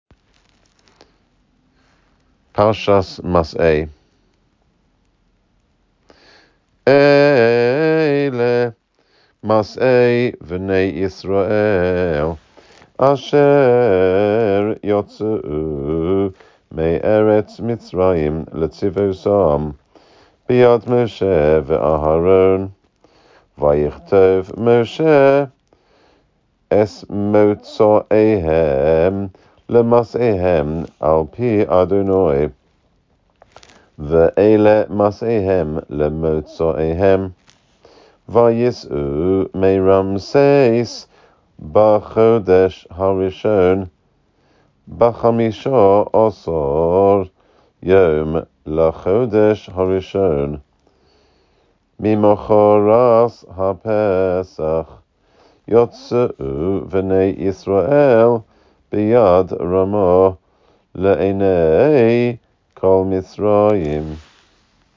A few pesukim from the beginning of each parashah, in Ashkenazi pronunciation. Pupils can learn from these in order to prepare for their turn to lein in the Shabbos Assembly.